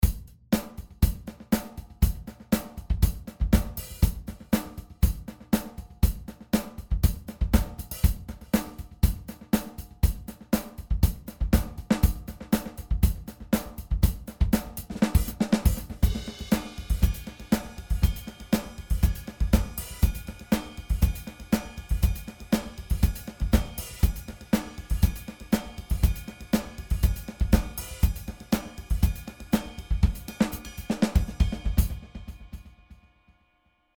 Einem Beat kann Uhbik-D zu ungeahntem Schwung verhelfen. Hier unser bereits bekannter Groove ohne den Effekt: